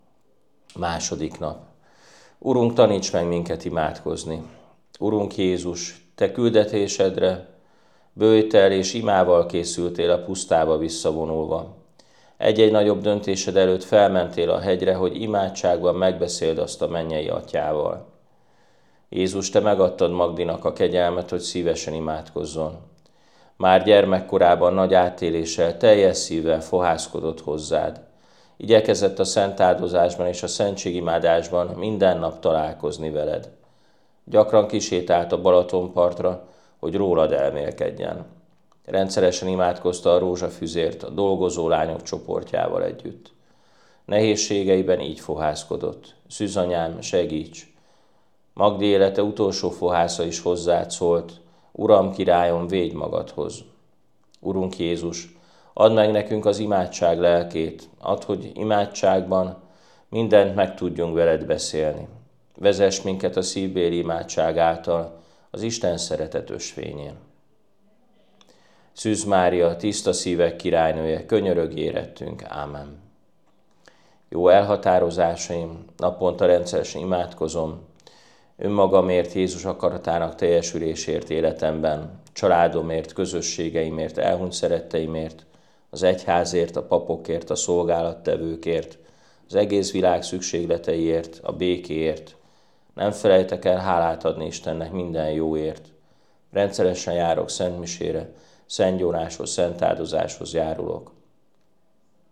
helynök atya felolvasásában: